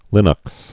(lĭnŭks)